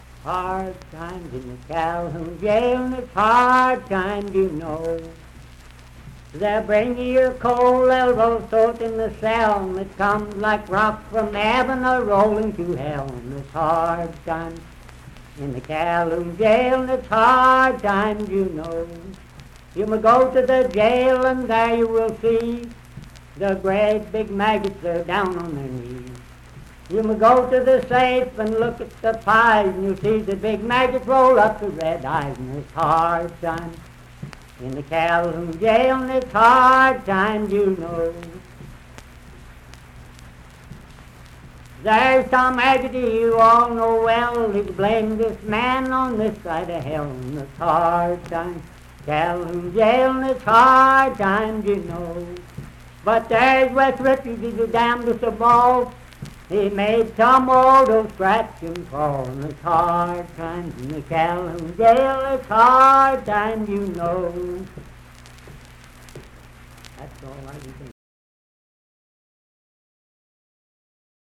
Unaccompanied vocal music and folktales
Voice (sung)